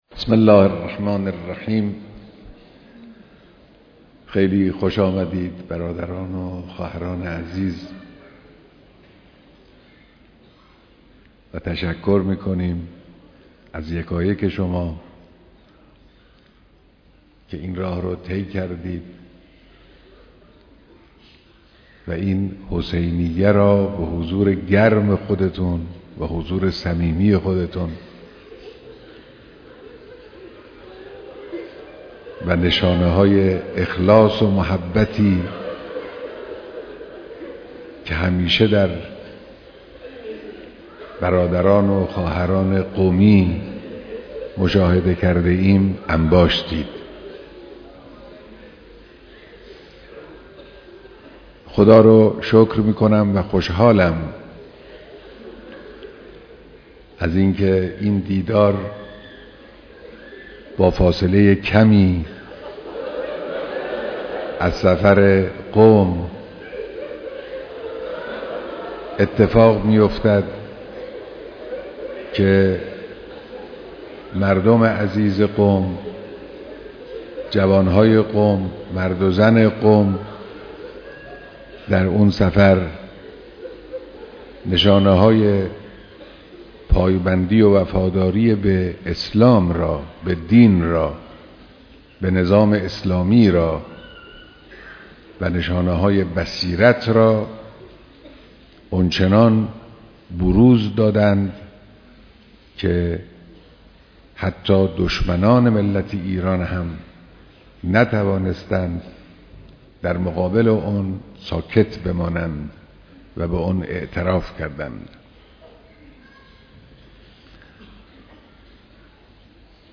بیانات در دیدار مردم قم در سالروز قیام 19 دی